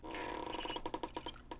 lever.wav